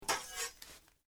Desgarre de tela
Sonidos: Hogar Costuras